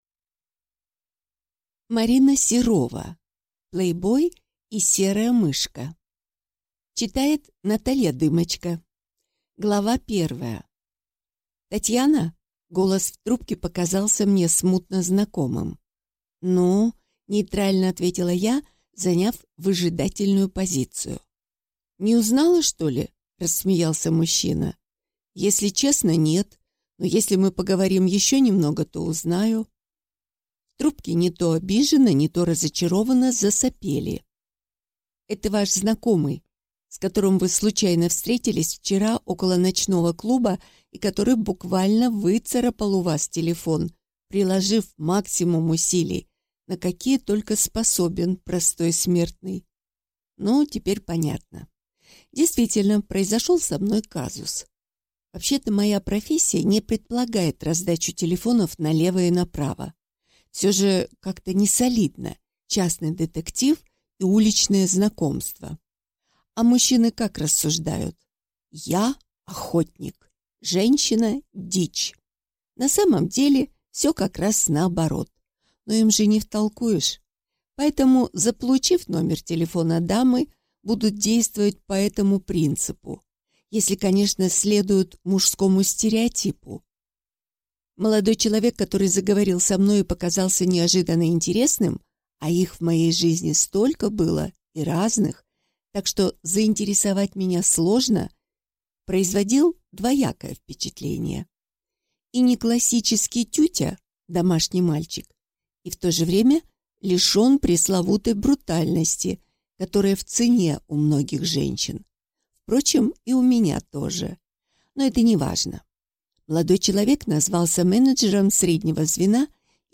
Аудиокнига Плейбой и серая мышка | Библиотека аудиокниг